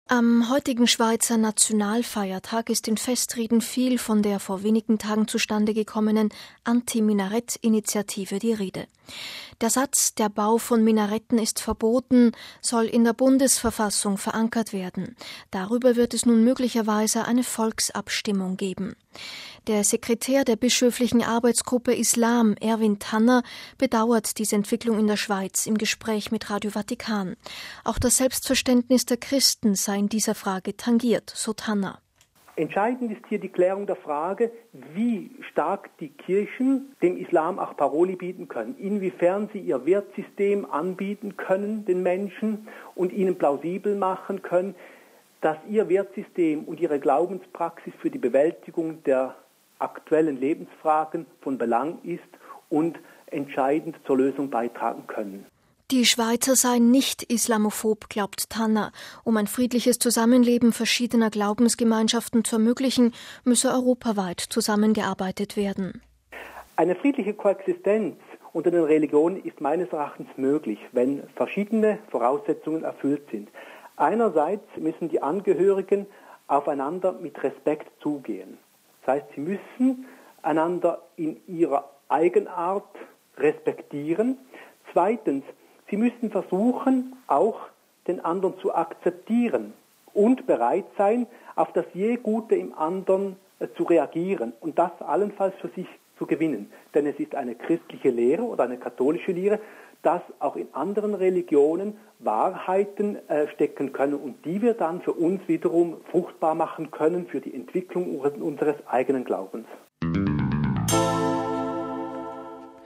bedauert die Entwicklung im Gespräch mit Radio Vatikan.